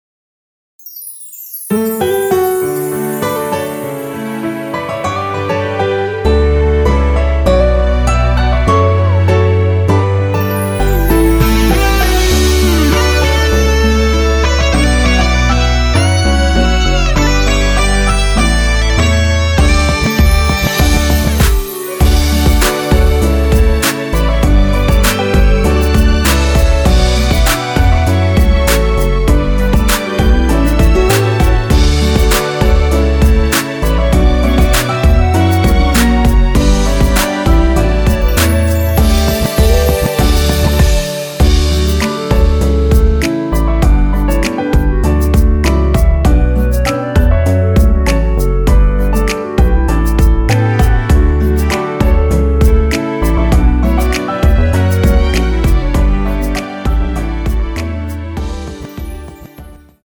대부분의 남성분이 부르실수 있는키로 제작 되었습니다.(미리듣기 참조)
원키에서(-9)내린 MR입니다.
멜로디 MR이라고 합니다.
앞부분30초, 뒷부분30초씩 편집해서 올려 드리고 있습니다.
중간에 음이 끈어지고 다시 나오는 이유는